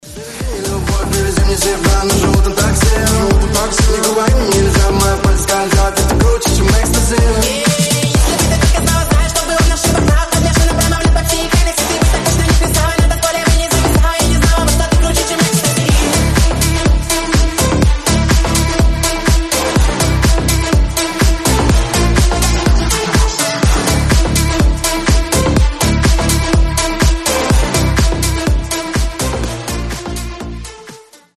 • Качество: 320, Stereo
мужской голос
громкие
Club House
быстрые
ремиксы